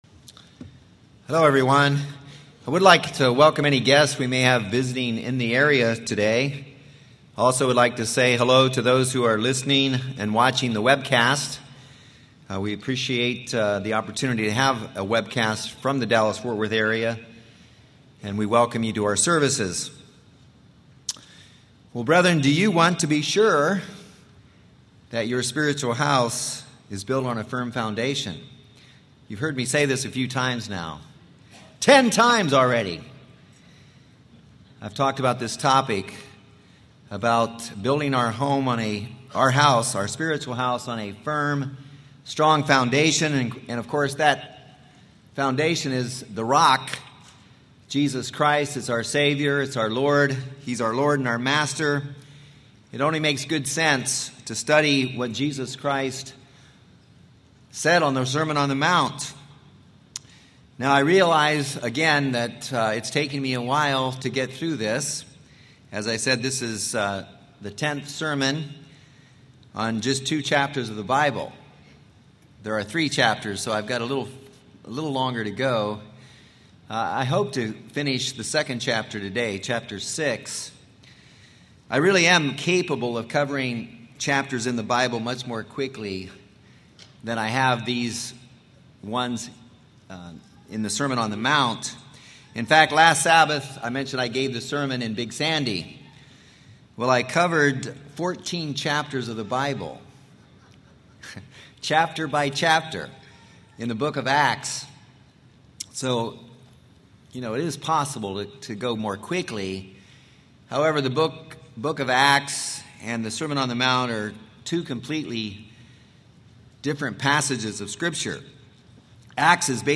This is a continuation of the sermon on the mount series.